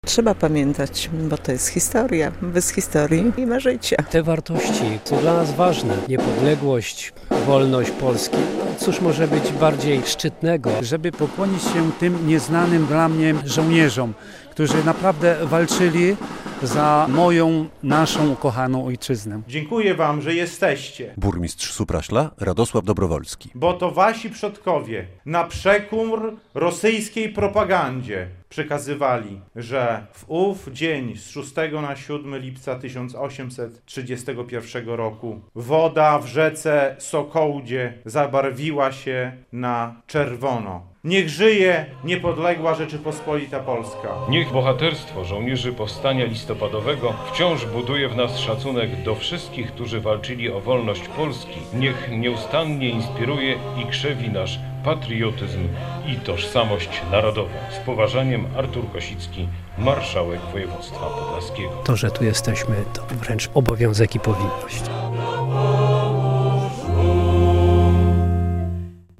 Mieszkańcy i władze regionu uczcili pamięć poległych w bitwie pod Sokołdą - relacja